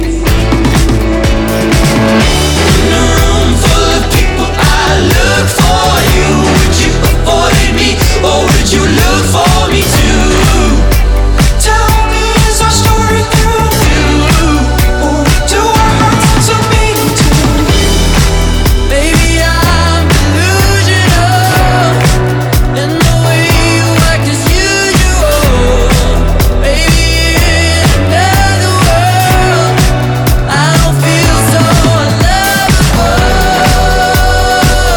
Indie Rock Alternative
Жанр: Рок / Альтернатива